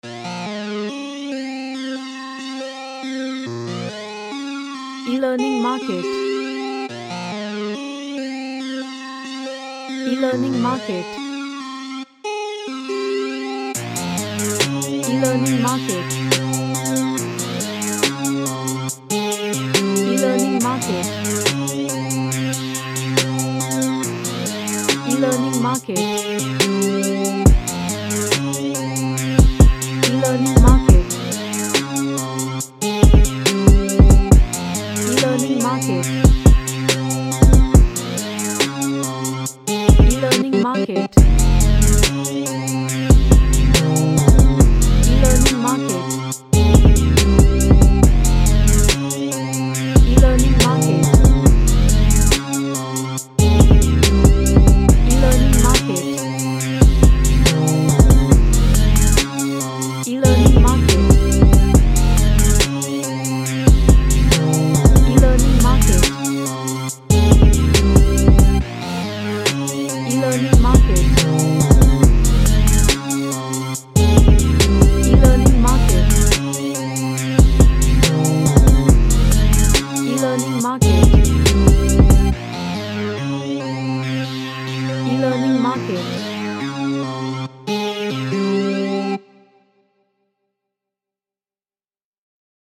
A hard energetic pop track
EnergeticDark